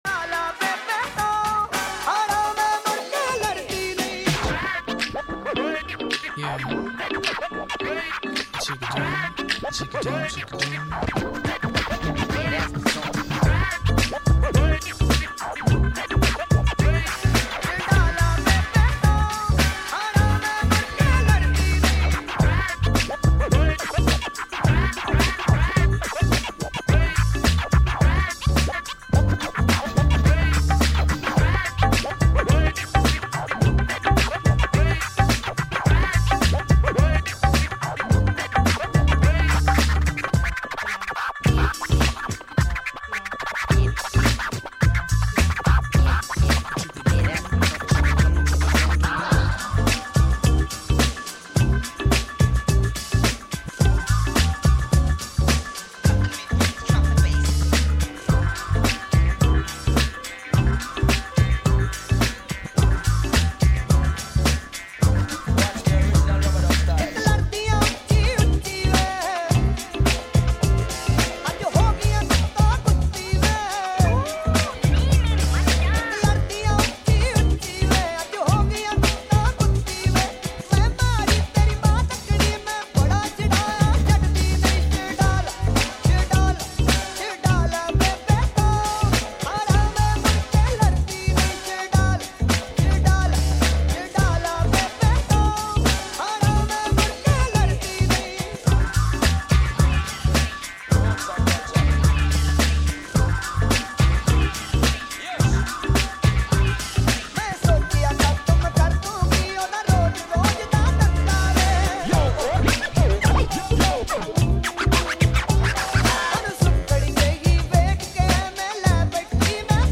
Asian HipHop Remix